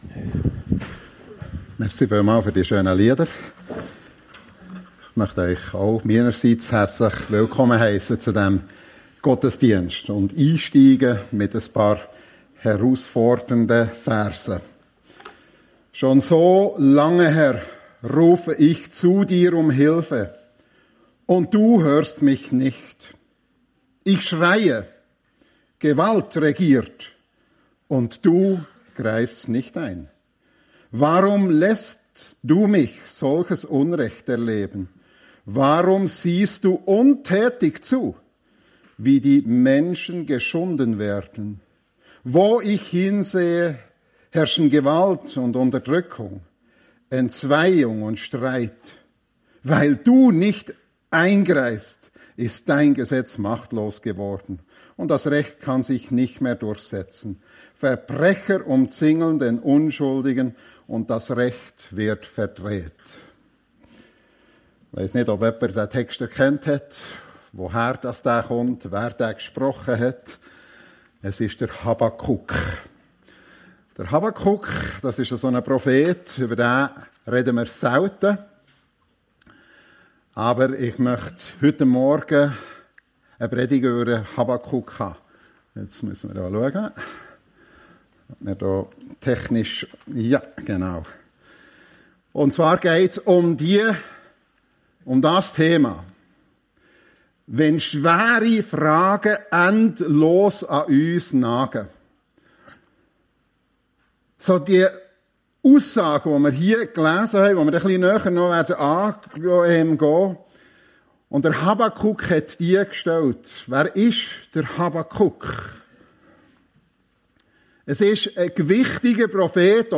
FEG Sumiswald - Predigten Podcast